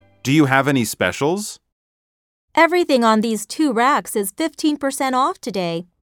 實境對話→